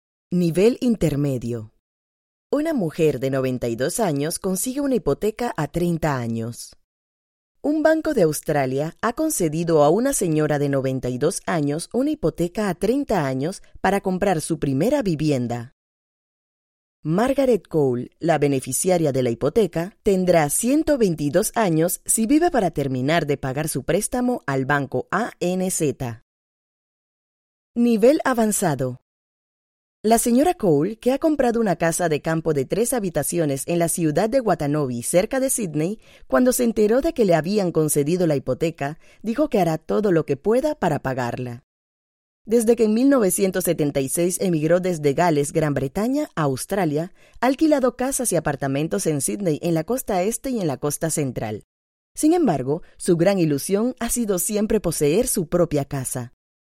Cursos de idiomas / E-Learning
Cursos de idiomas